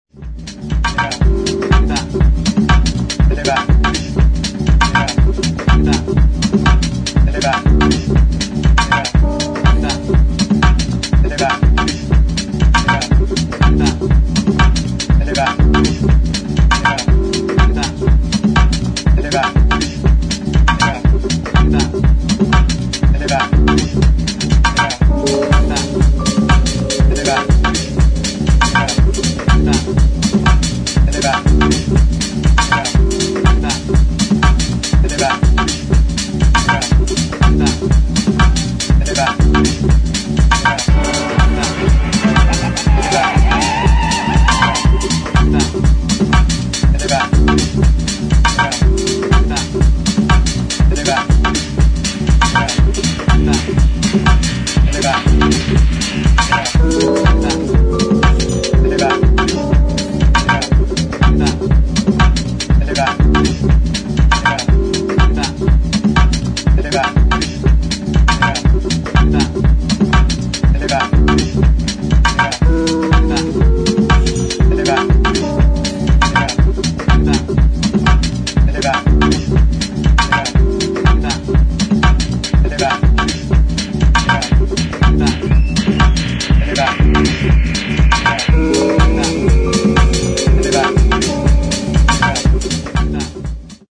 [ DEEP HOUSE ]